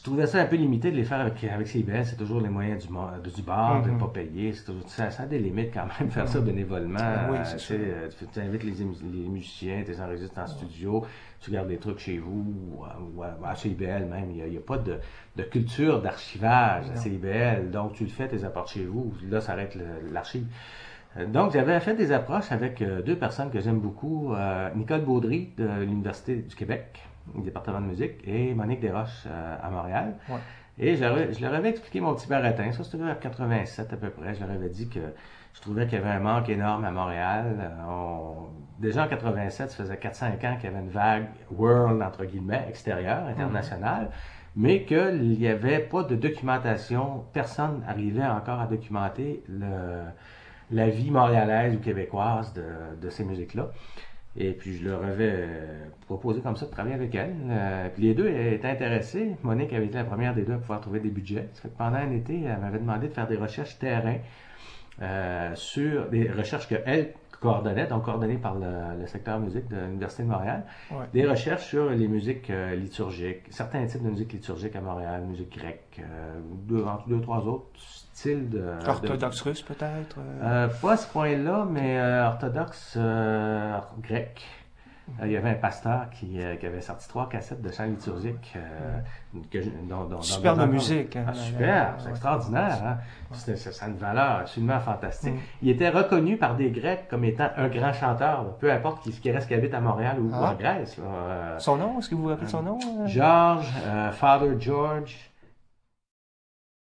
Extraits sonores des invités
Extraits des entrevues sur le patrimoine sonore :